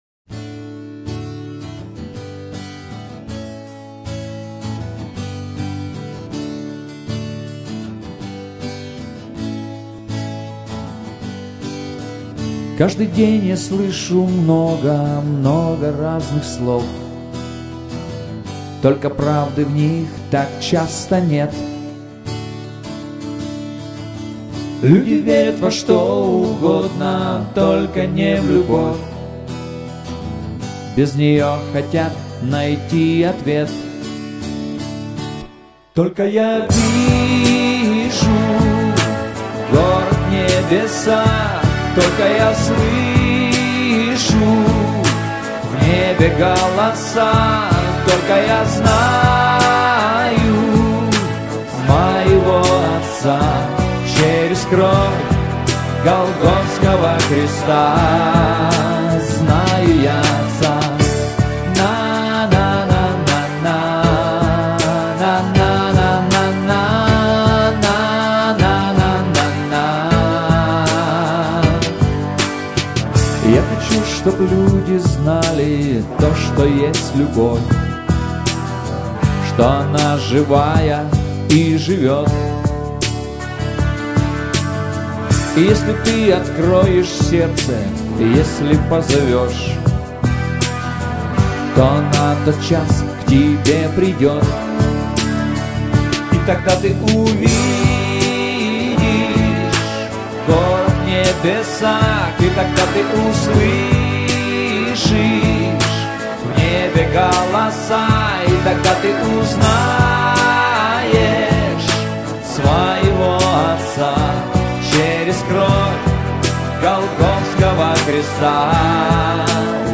3911 просмотров 3664 прослушивания 466 скачиваний BPM: 79